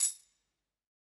Tambourine Zion 1.wav